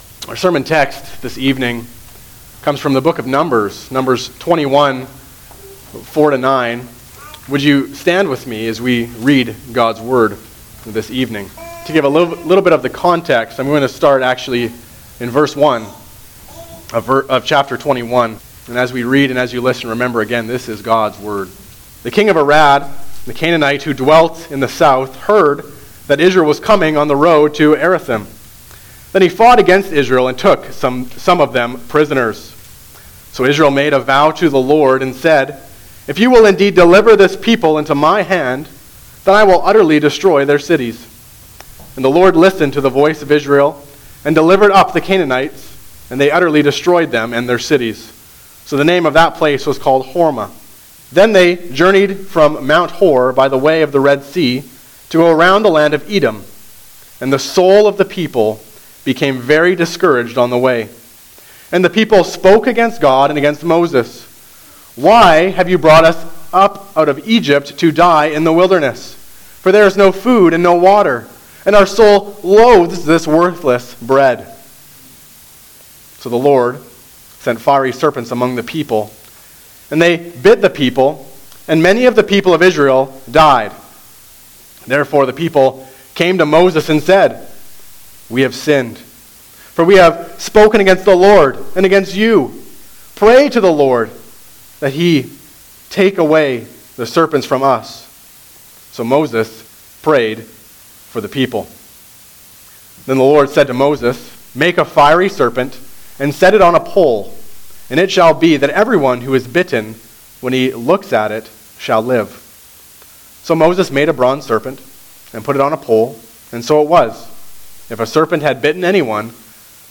Guest Preachers Passage: Numbers 21:4-9 Service Type: Sunday Evening Service Download the order of worship here .